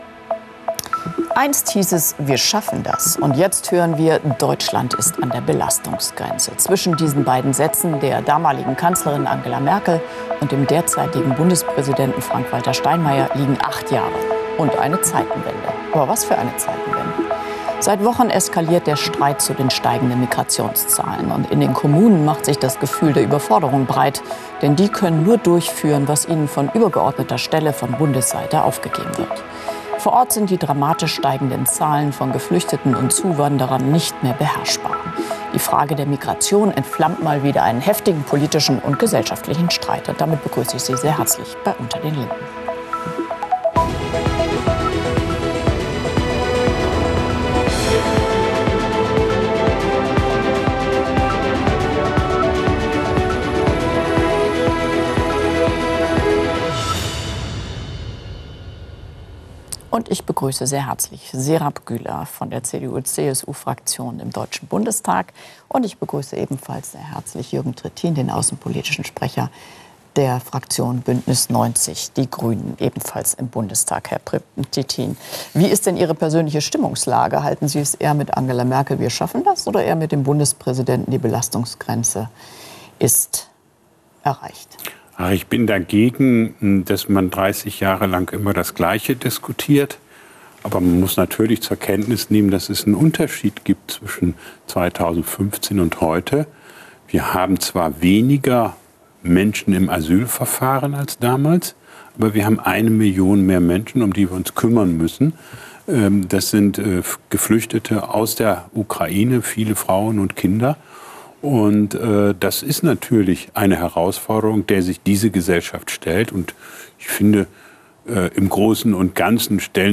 „unter den linden“ ist das politische Streitgespräch bei phoenix.